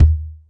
Kick_11_b.wav